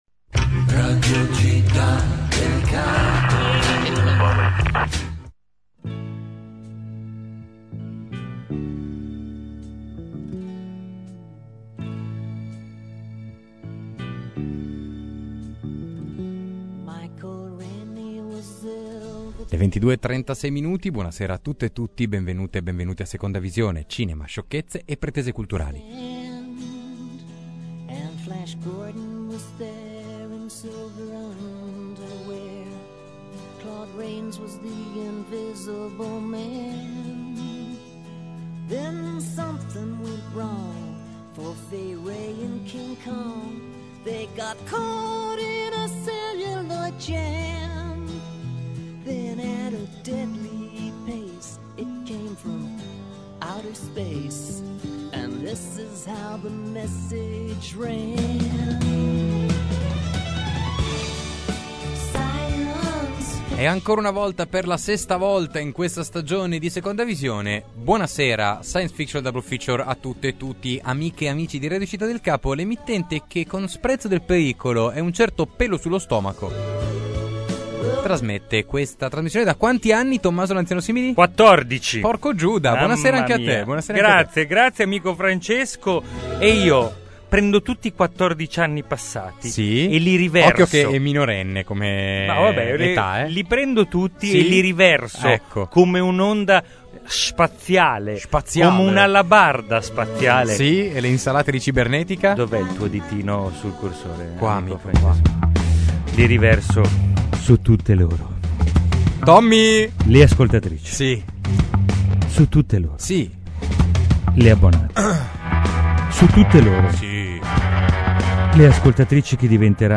– Interstellar, di Christopher Nolan – Intervista a Dario Argento (da La colazione dei campioni) – Trailer: Il leone di vetro – Sils Maria, di Olivier Assayas